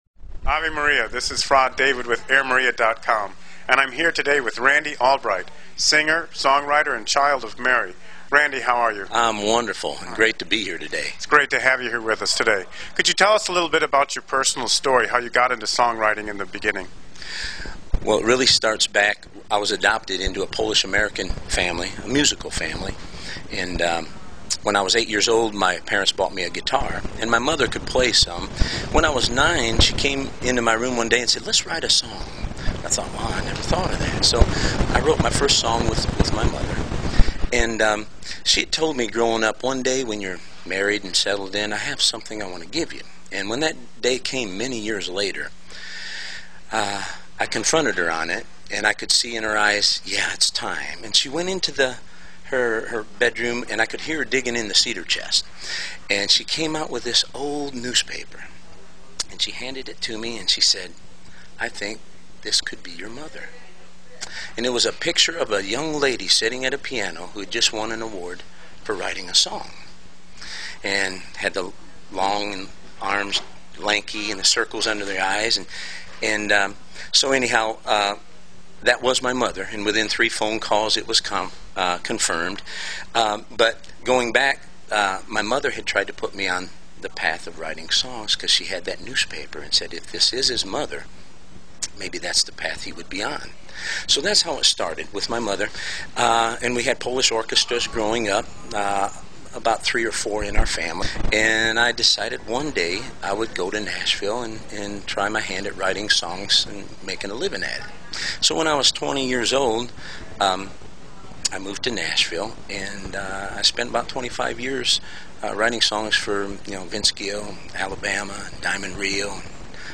We apologize for the poor audio sound, we had a lot of problems that day with audio.